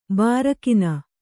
♪ bārakina